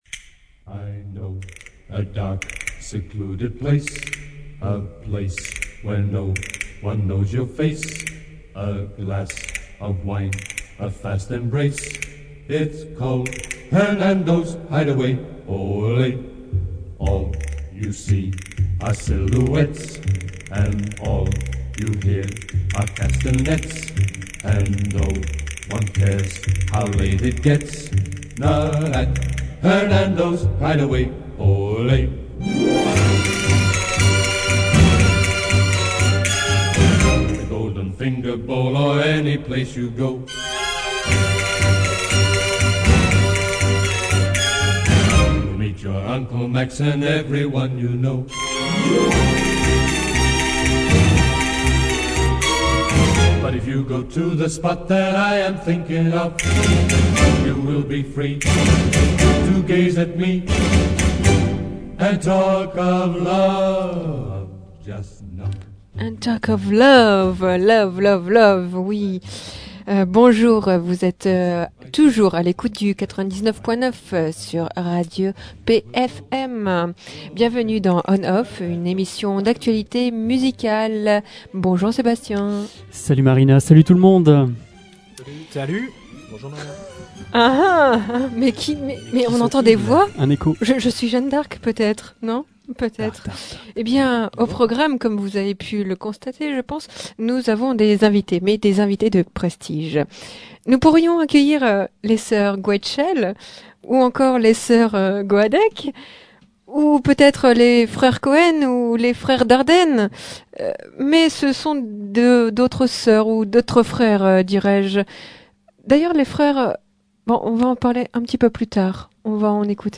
un groupe post-punkrock d'Arras-Lens.